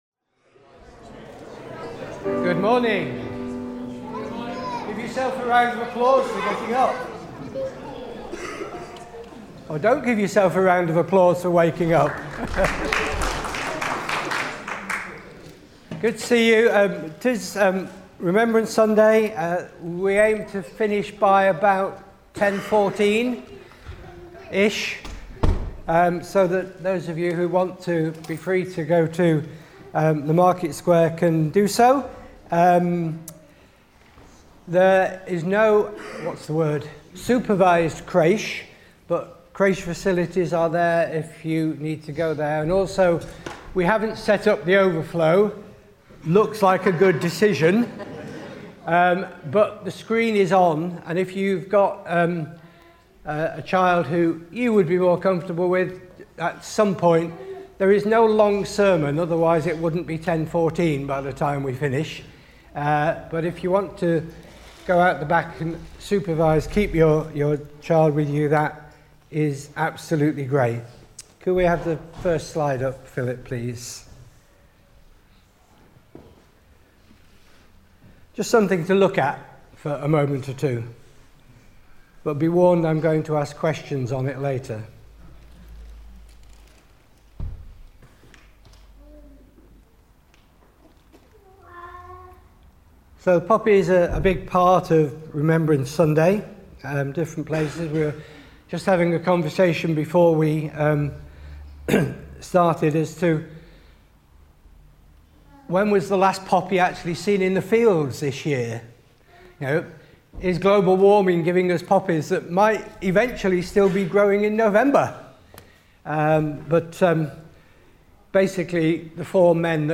Remembrance Sunday Service Type: Morning Service On Remembrance Sunday